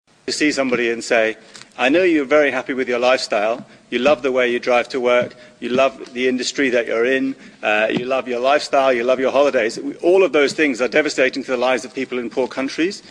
Just as an example, listen to the very short audio clip of an individual speaking at a United Nations meeting about middle class type people living in developed countries: